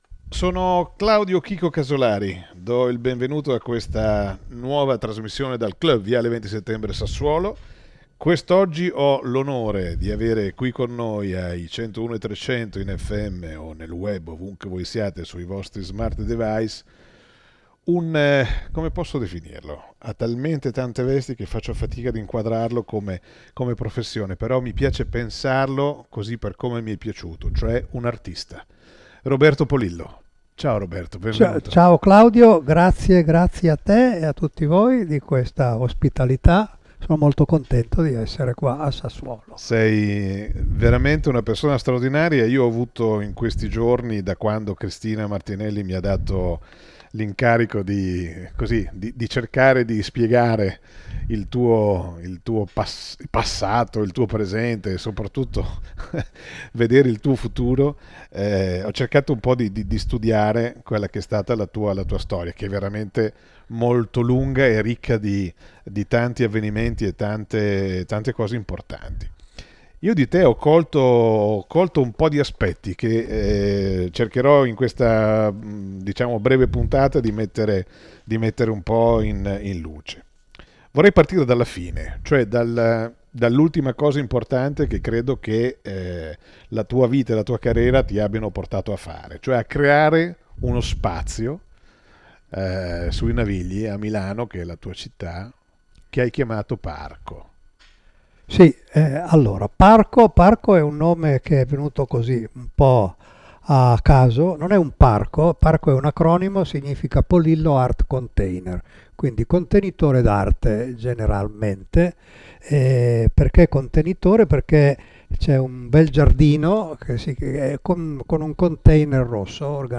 Le interviste di Linea Radio al Clhub di viale XX Settembre a Sassuolo